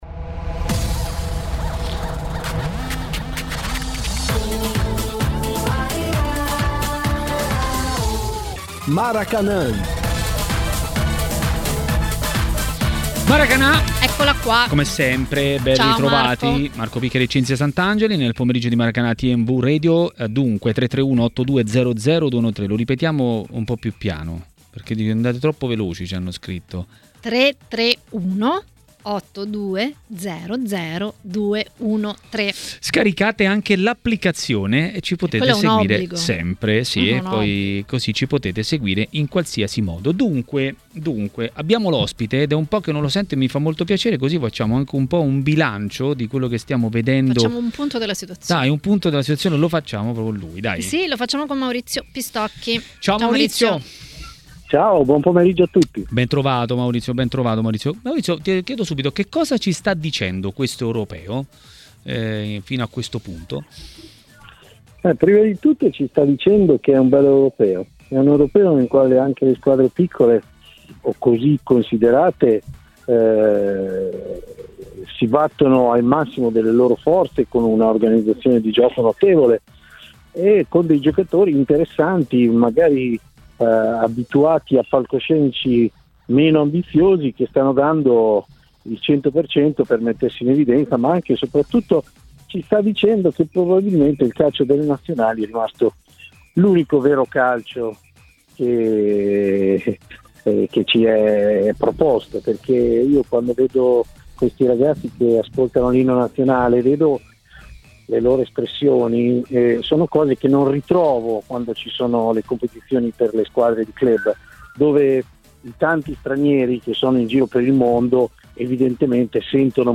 Per fare un bilancio di Euro 2020 a TMW Radio, durante Maracanà, è intervenuto il giornalista Maurizio Pistocchi.